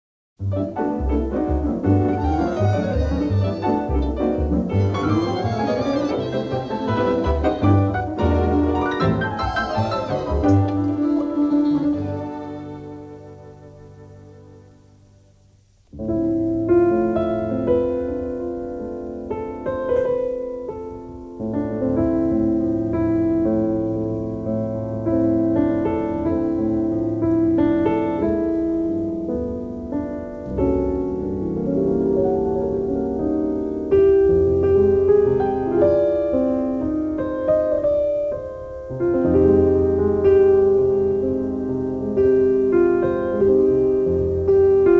the balance of the CD is in mono.